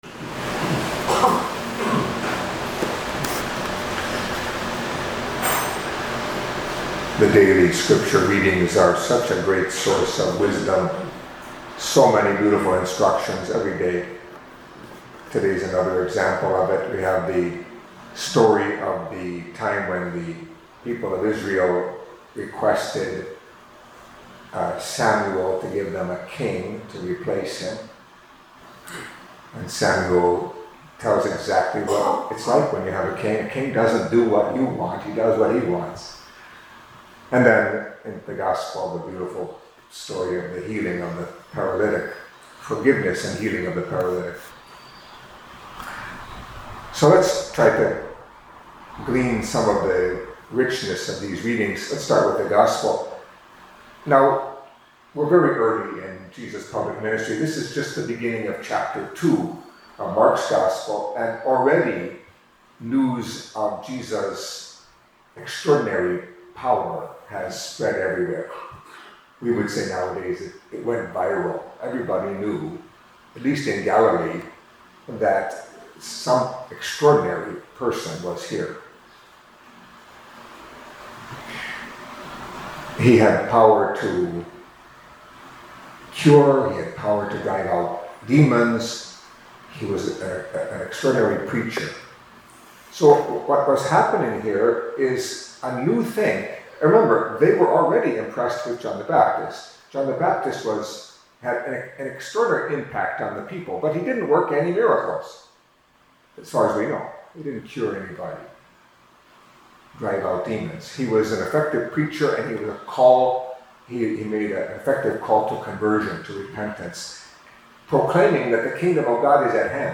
Catholic Mass homily for Friday of the First Week in Ordinary Time